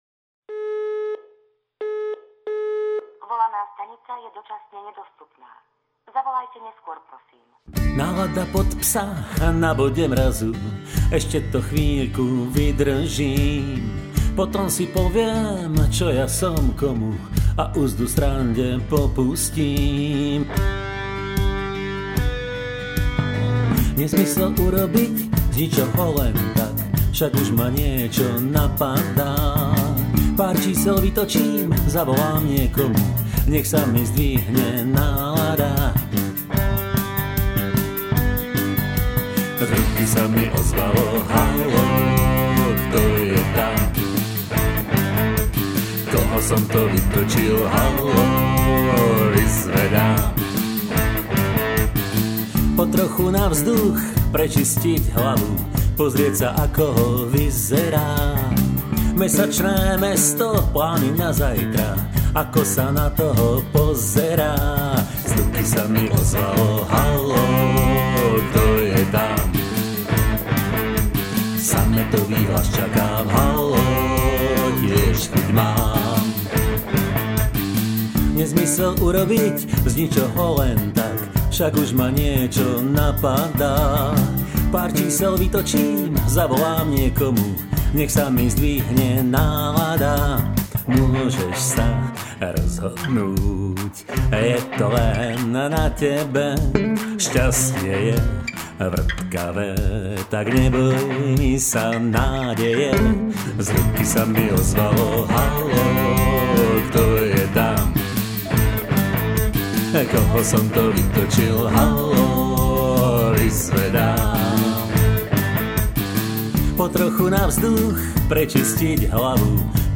12 st. gitara, djembe, fúkacia harmonika, koncovka